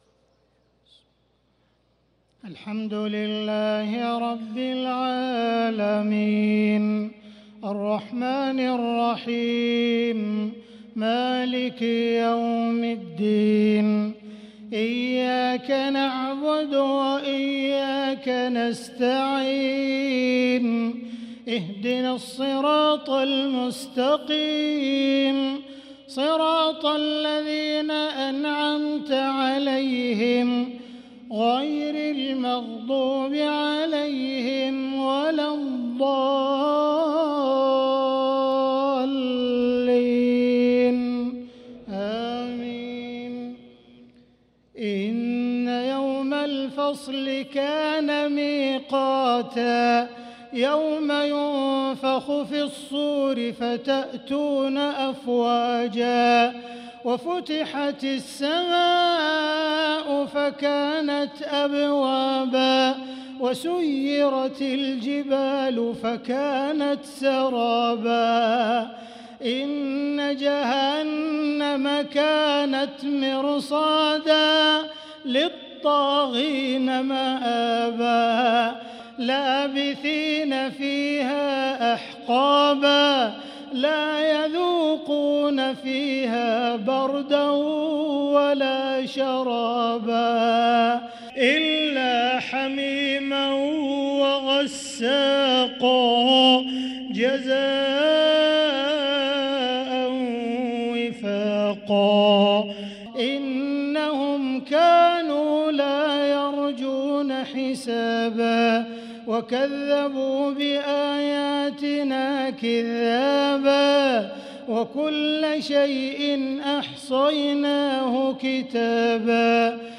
صلاة العشاء للقارئ عبدالرحمن السديس 8 شعبان 1445 هـ